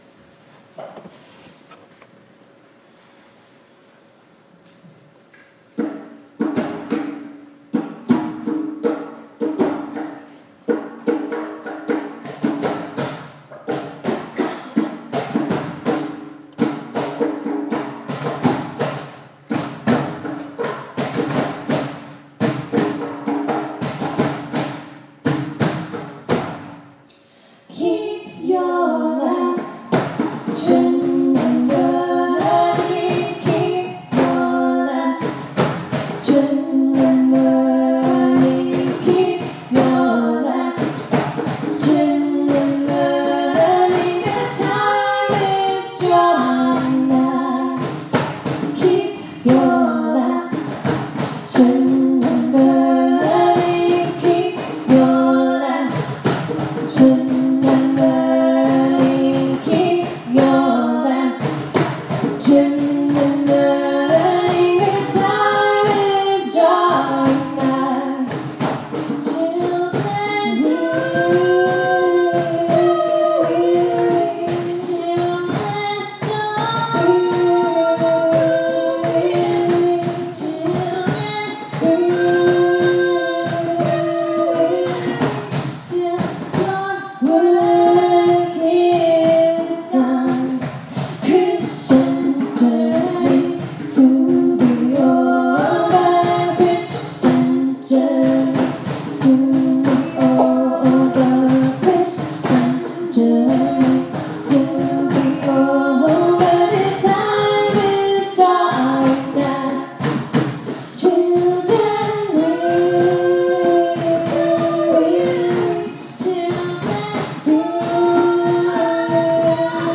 • Saturday night, at the retreat, in the worship set. Specifically – in the drum circle.